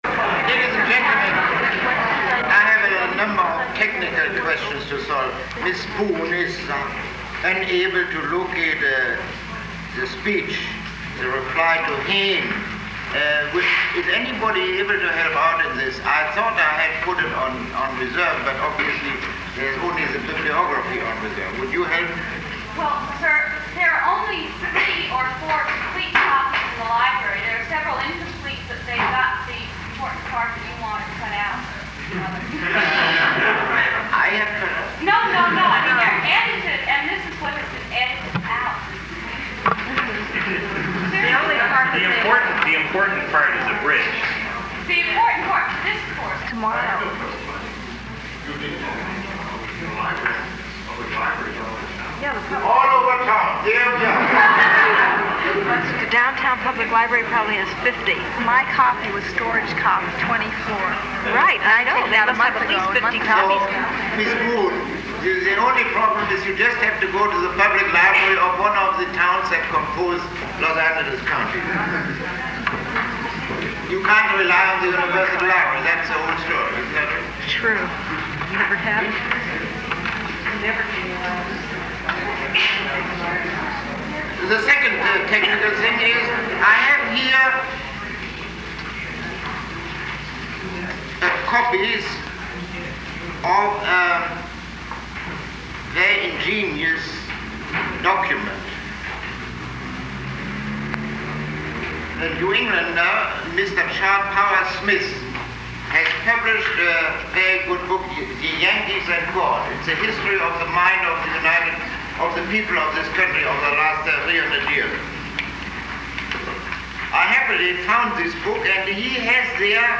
Lecture 22